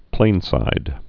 (plānsīd)